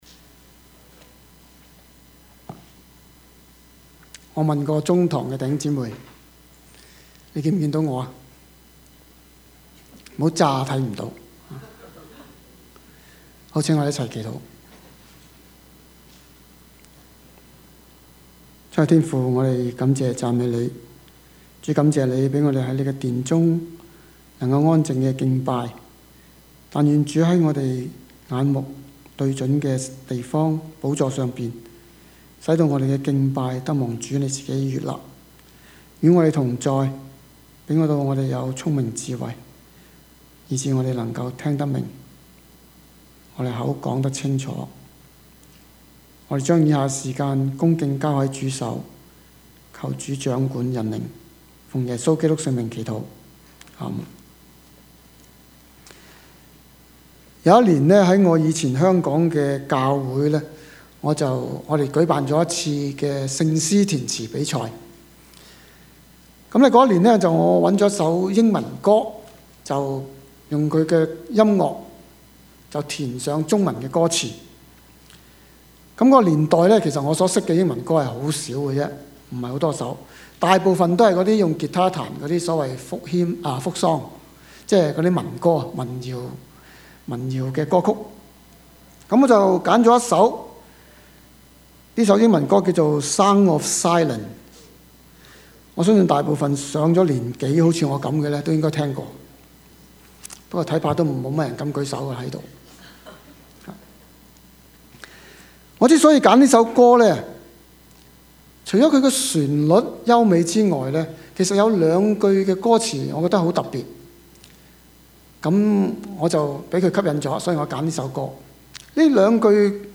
Service Type: 主日崇拜
Topics: 主日證道 « 權柄何在?